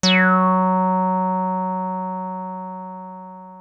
303 F#3 9.wav